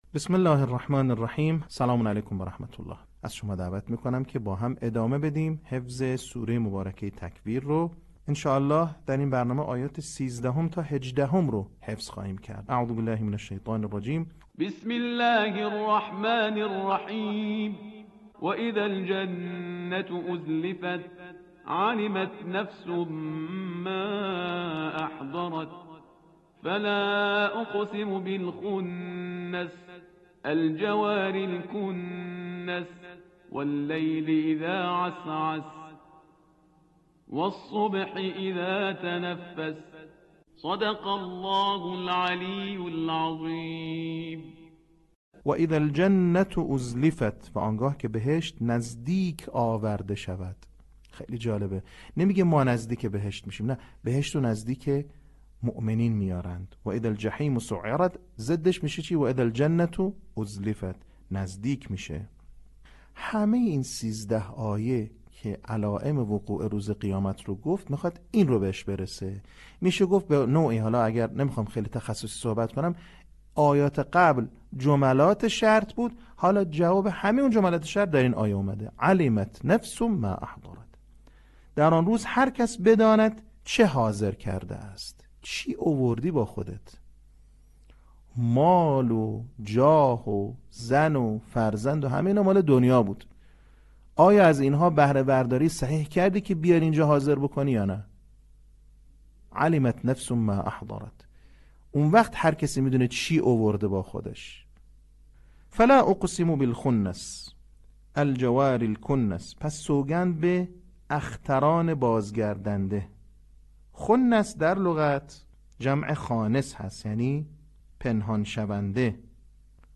صوت | بخش سوم آموزش حفظ سوره تکویر